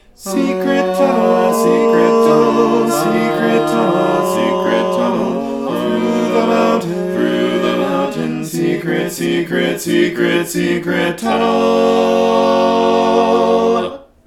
Key written in: G♭ Major
How many parts: 4
Type: Barbershop
All Parts mix:
Learning tracks sung by